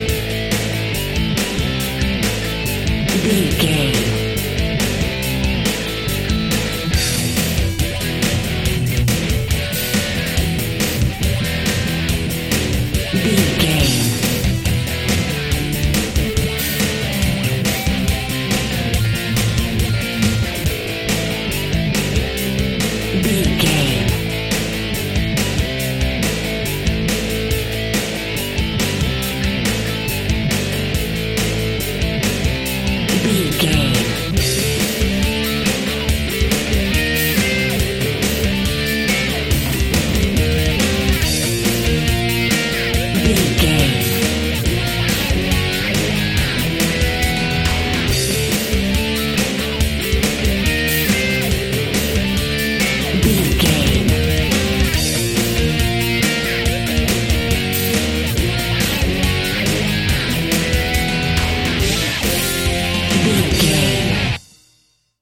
Aeolian/Minor
drums
electric guitar
bass guitar
Sports Rock
hard rock
aggressive
energetic
intense
nu metal
alternative metal